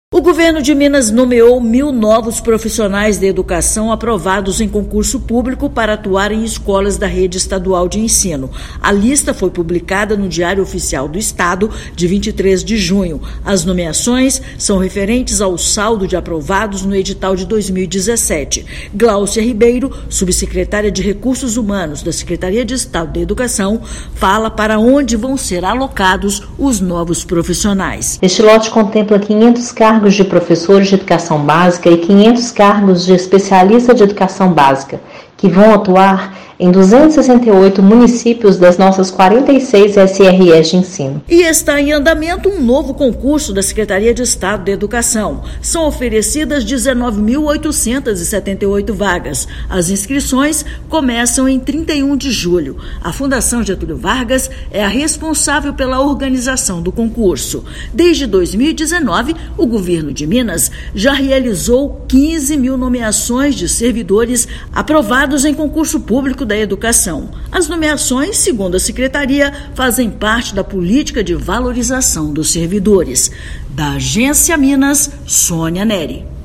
Agora, já são 2 mil nomeados dos aproximadamente 5 mil candidatos aprovados que poderão ser chamados pela SEE/MG. Ouça matéria de rádio.